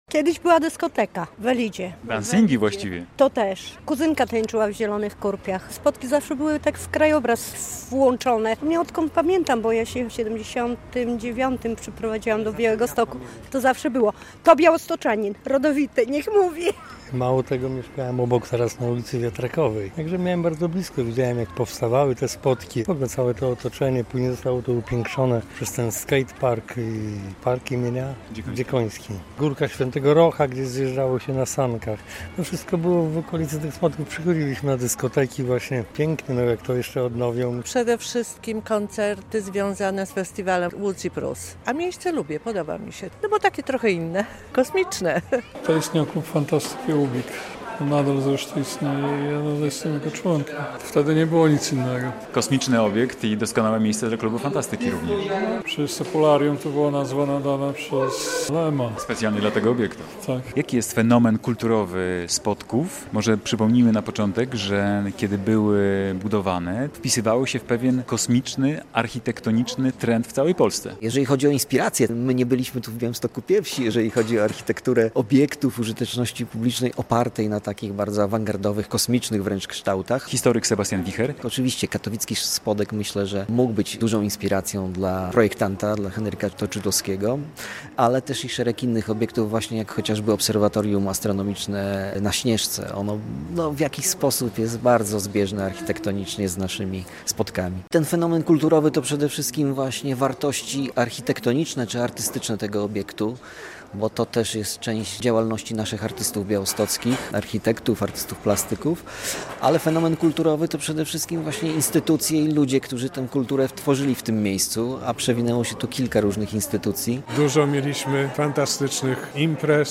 Białostockie "Spodki" obchodzą złoty jubileusz - relacja
Wicemarszałek województwa Sebastian Łukaszewicz mówi, że dzisiejsze uroczystości, to okazja, żeby przypomnieć, jak przed remontem wyglądają "Spodki".